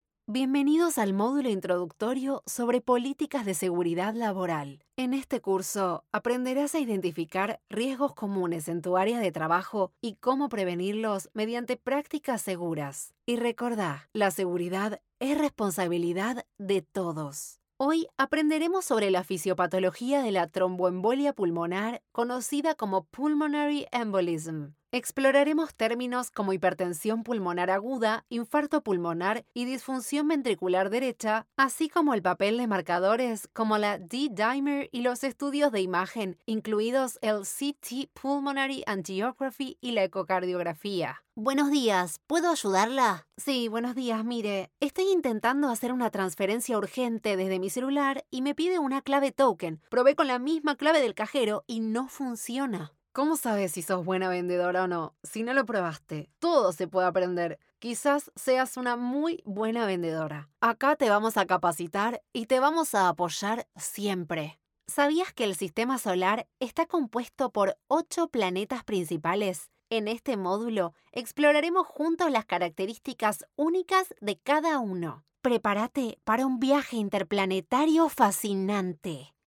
Español (Argentino)
E-learning
Soy una joven locutora argentina, con experiencia en teatro escénico y musical, que desde hace varios años se ha sumergido de lleno en la creación de voces en español latinoamericano.
Soy versátil y enérgica aunque también puedo darle a los proyectos intensidad, calma, calidez y madurez.
Cabina Vocal Demvox ECO100
MIC: TLM 103
Mezzosoprano